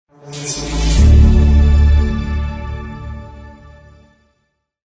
16-Bit Stereo 16位立体声
时尚动感片头音乐